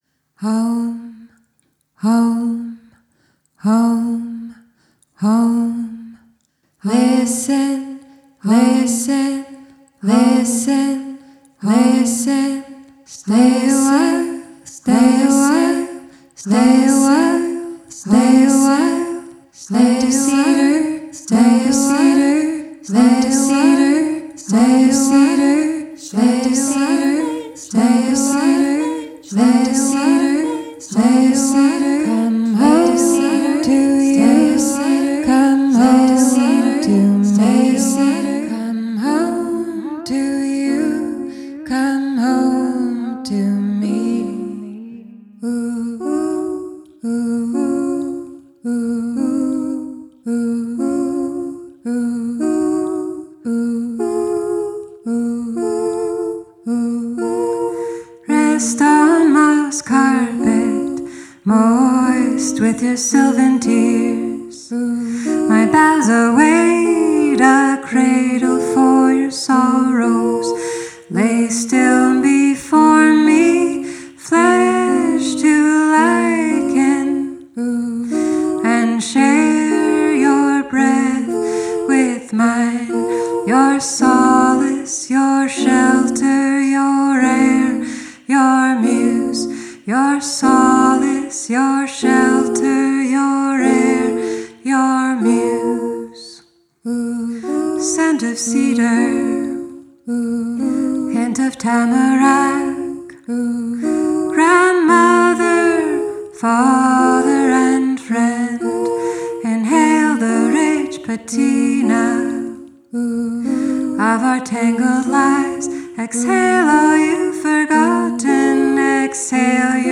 deeply soulful vocals
A capella version